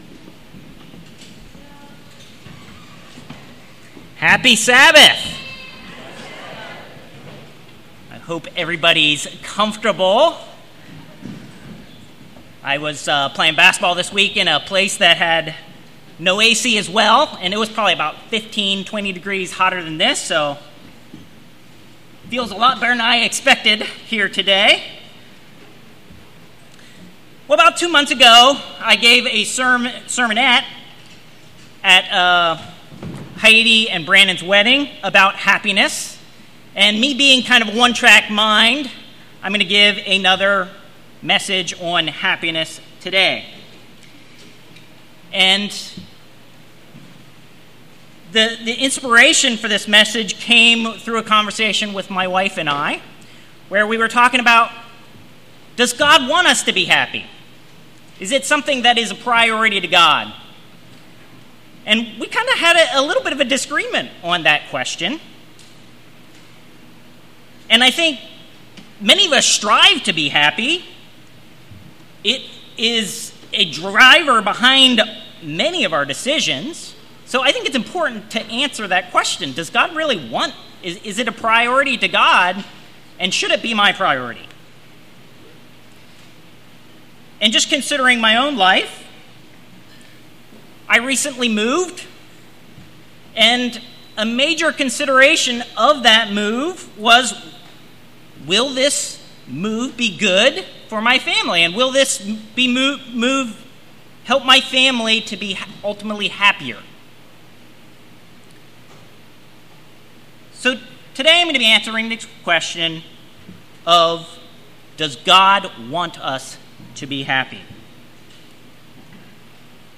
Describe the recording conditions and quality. Given in Lewistown, PA